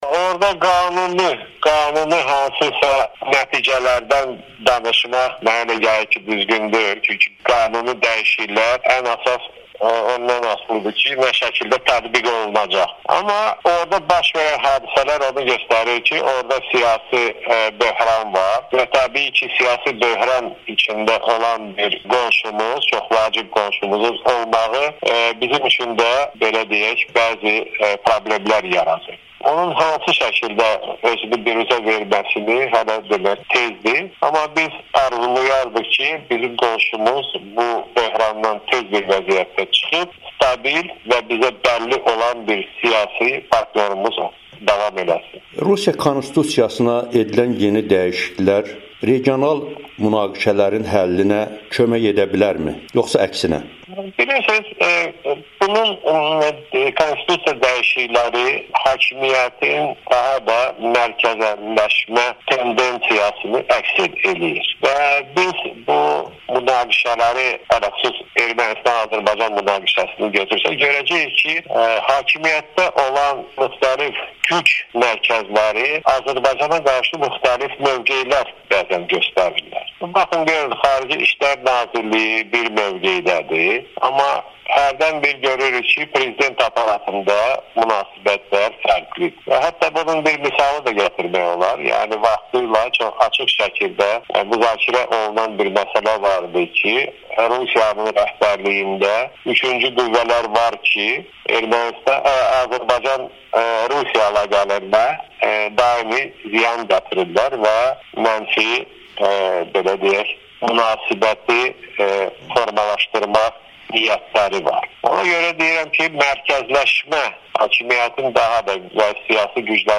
Azərbaycanın keçmiş xarici işlər naziri Tofiq Zülfiqarov Amerikanın Səsinə müsahibəsində bildirib ki, Rusiyada konstitusiya dəyişiklikləri ölkədə siyasi böhranın mövcudluğunu göstərir.
Tofiq Zülfiqarov, keçmiş xarici işlər naziri